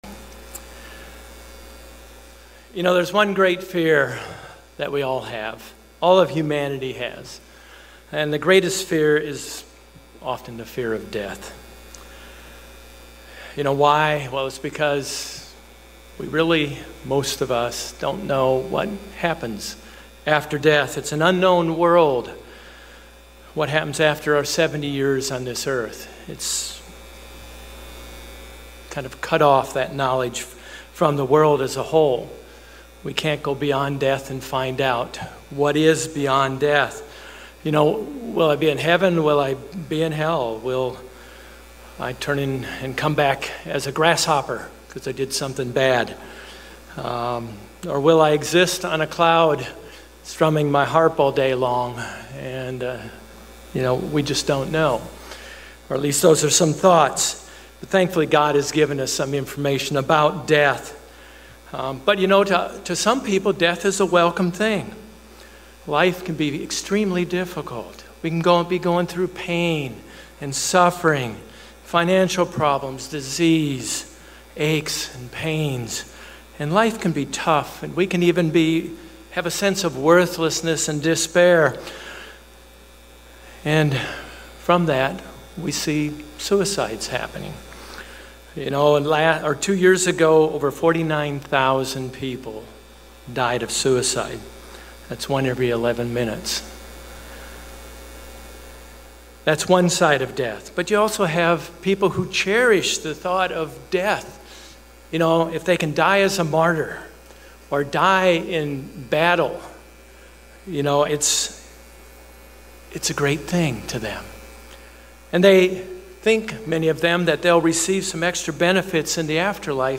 Given in Orlando, FL